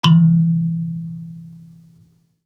kalimba_bass-E2-pp.wav